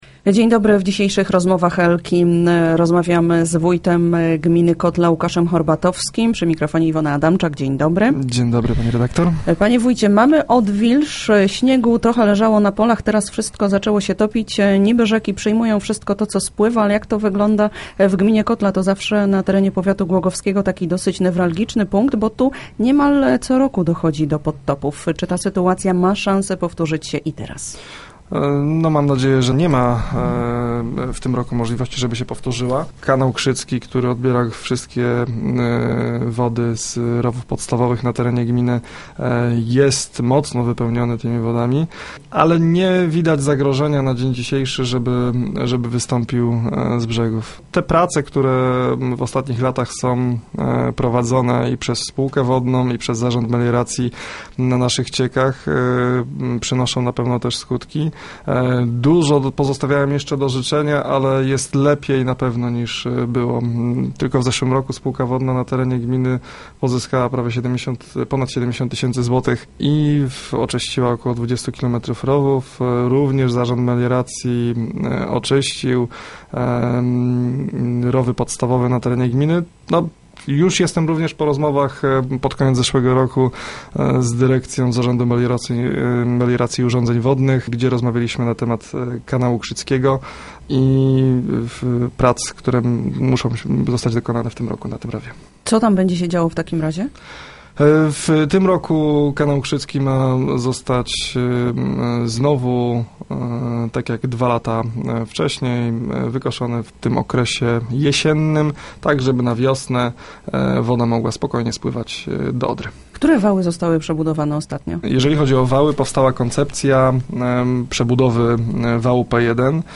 Newralgicznym miejscem jest kanał Krzycki na terenie gminy Kotla. Gościem poniedziałkowych Rozmów Elki był wójt gminy Łukasz Horbatowski.